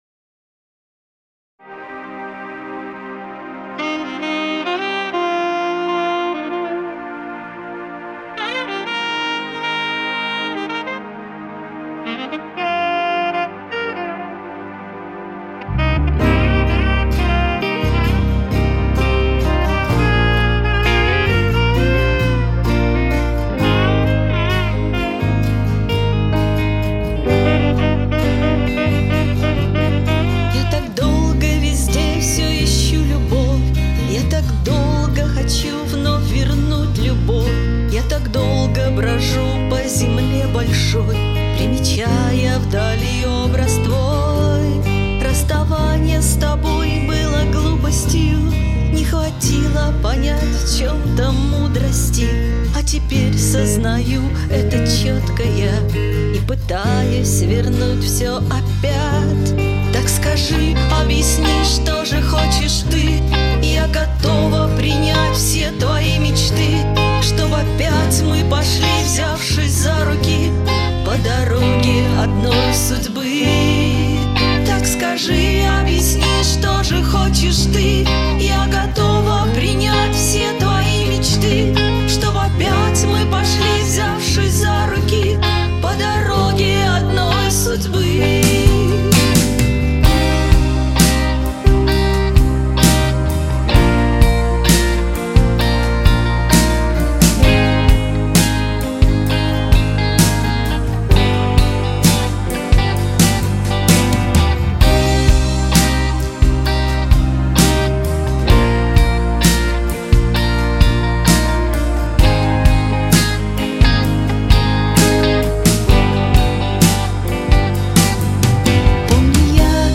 струнный квартет подыгрывает.....сам на скрипке скрипел 10 годков и млею
Что касается минусовки "Города...",то я сегодня закину её в каталог.Правда качество "оставляет желать" - всё звучит несколько "мидюшно".В общем,пока так...